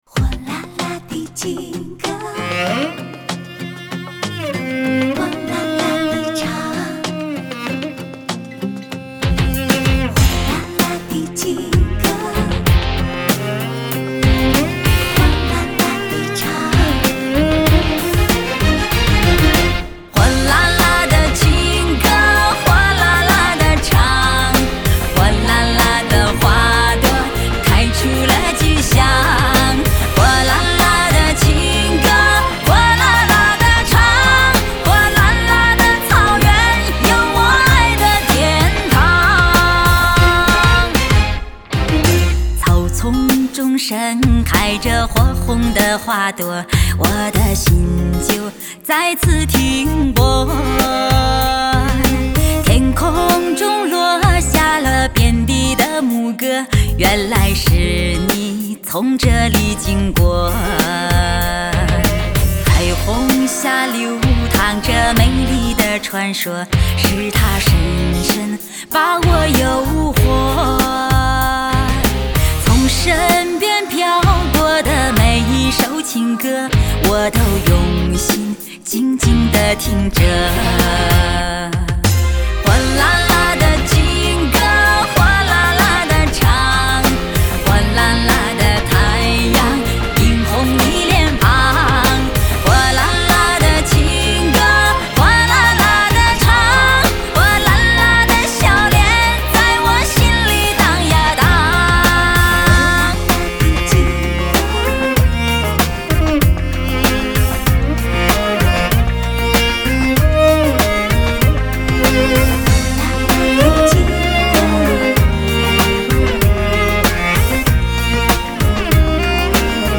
类别: 电音